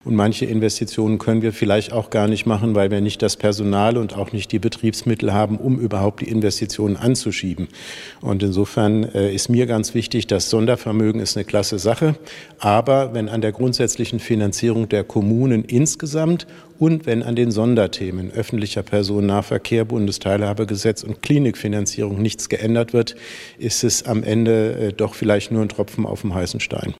Interview mit Frank Mentrup: "Die Städte stehen mit dem Rücken völlig an der Wand"